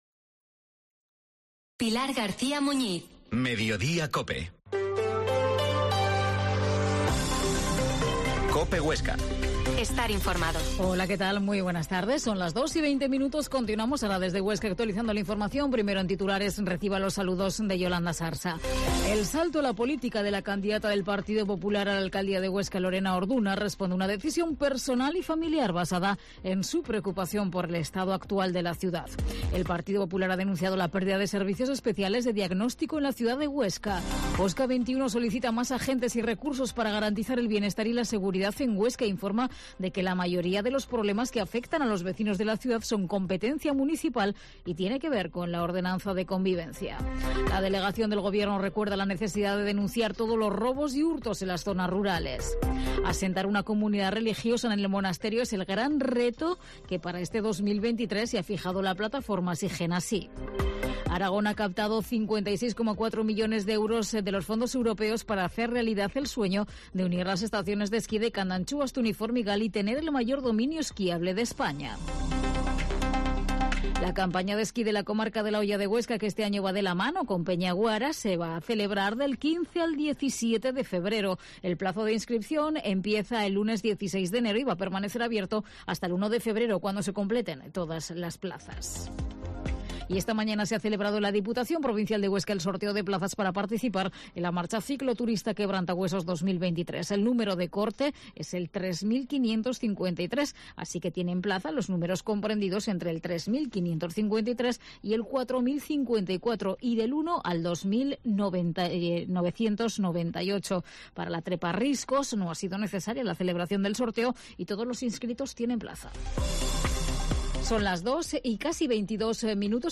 Informativo Mediodía en Huesca